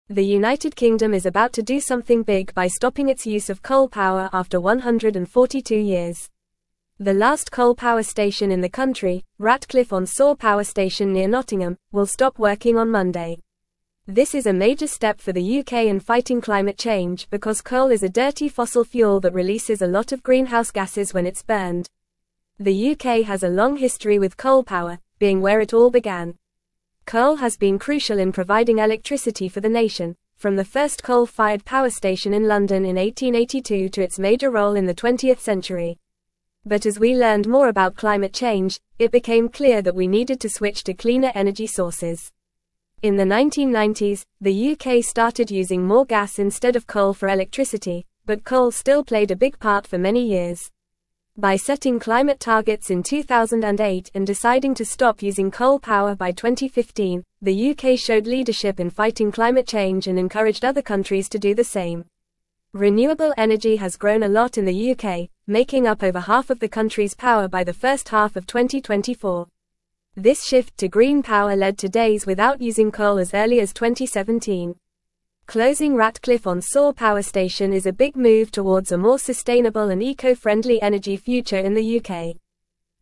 Fast
English-Newsroom-Upper-Intermediate-FAST-Reading-UK-Ends-142-Year-Coal-Reliance-Shifts-to-Renewables.mp3